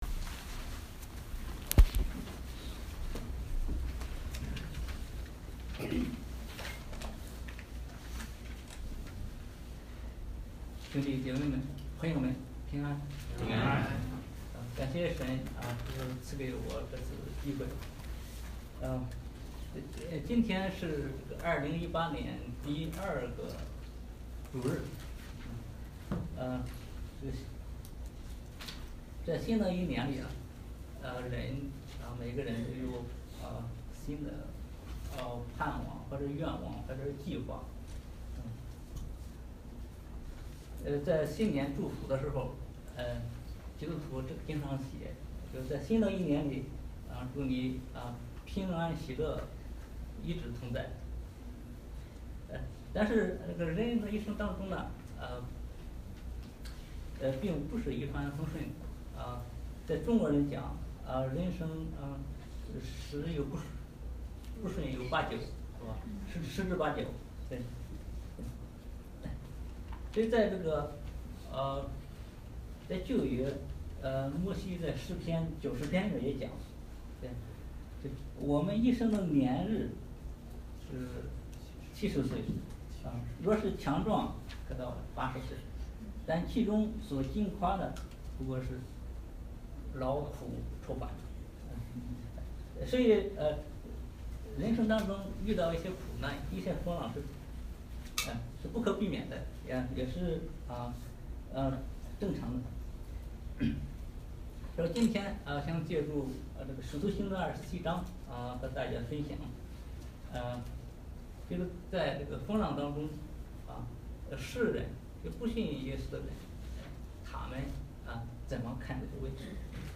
所有布道录音现都已转换成MP3格式，这样能用本网站内置播放器插件播放。